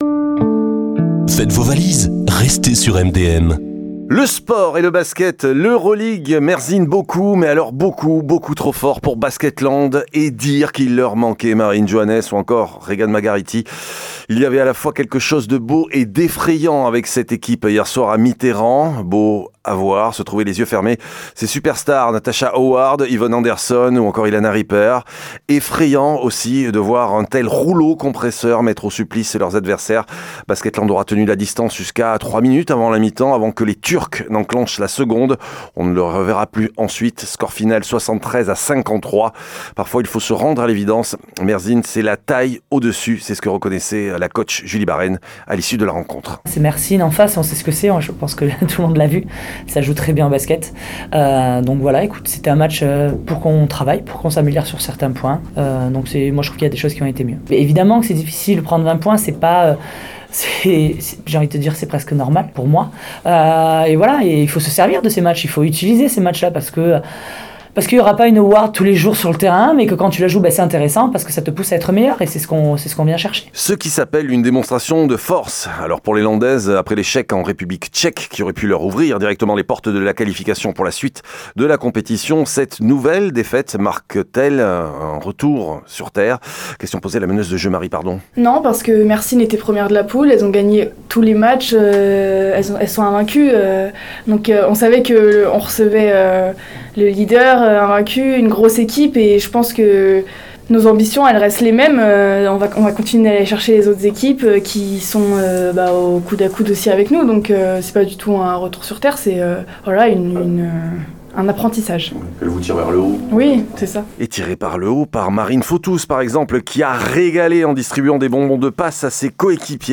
réactions au sortir du match